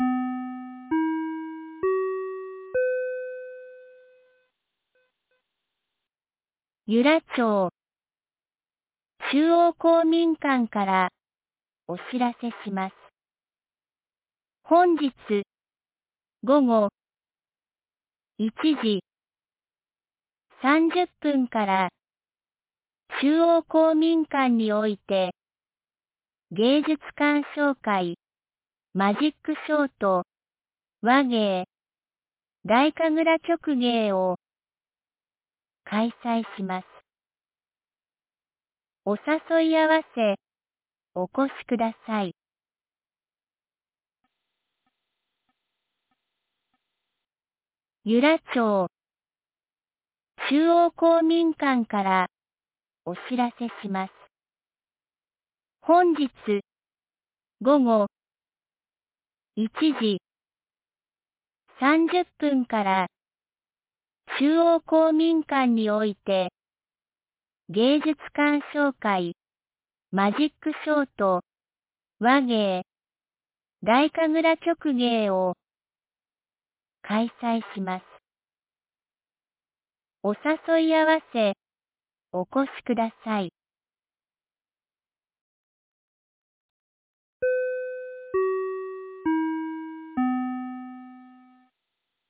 2026年02月01日 12時22分に、由良町から全地区へ放送がありました。